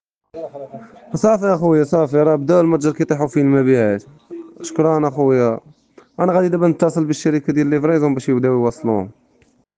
شهادات التلاميذ